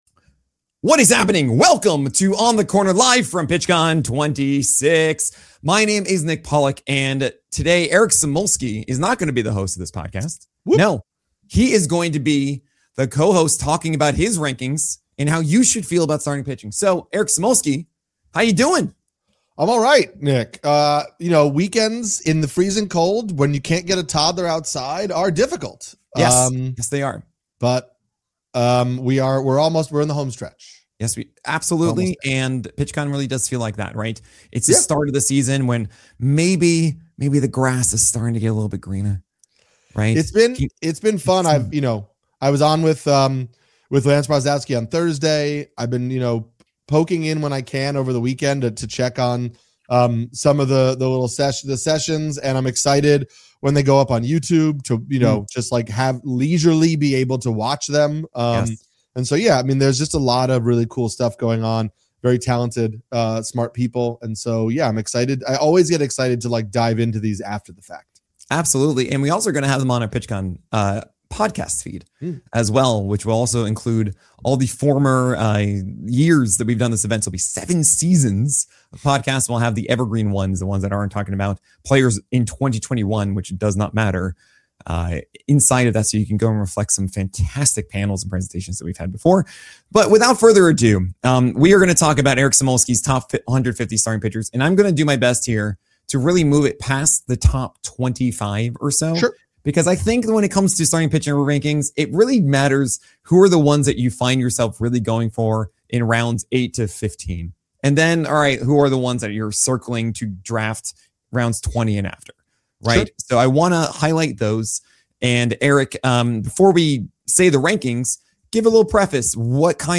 OTC 594 - LIVE FROM PITCHCON!